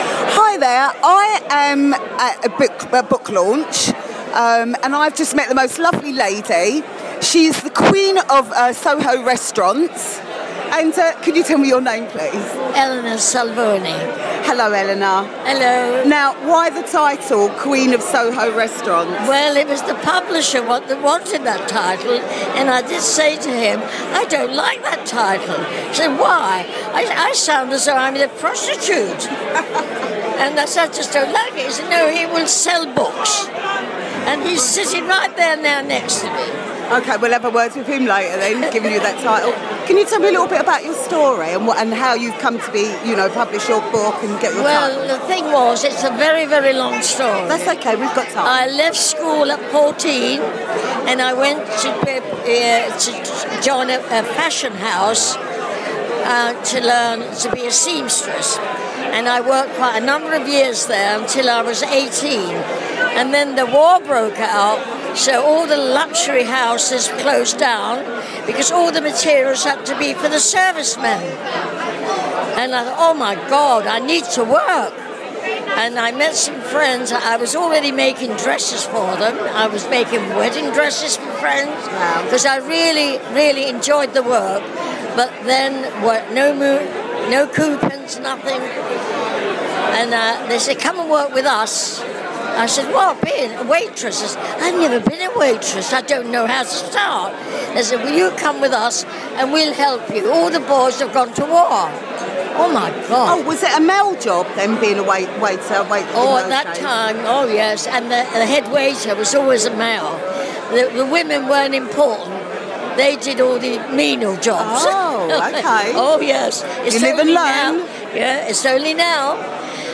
I'm at the launch of a new book